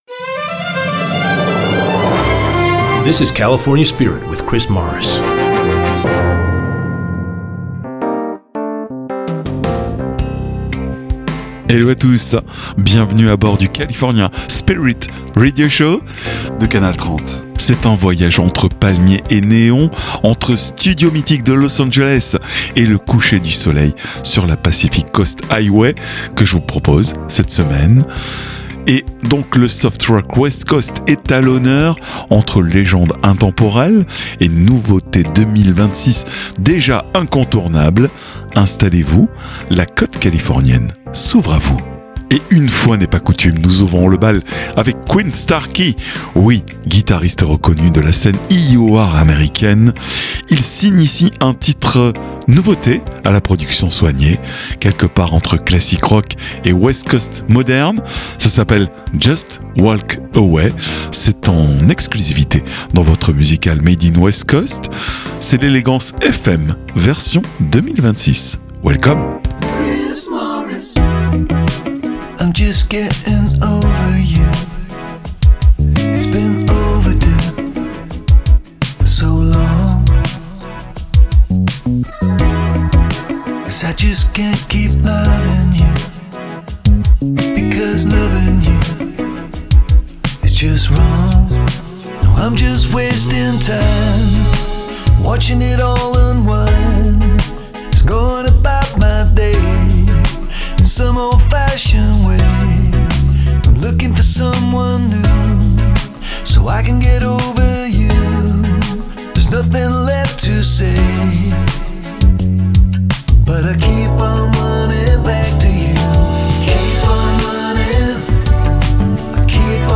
Le California Spirit est un concept unique inspiré des radios US , avec des PowersPlays (nouveautés) et ExtraGold (Oldies).
C’est un format musique californienne (Allant du Classic Rock en passant par le Folk, Jazz Rock, Smooth jazz) le tout avec un habillage visuel très 70’s et un habillage sonore Made in America.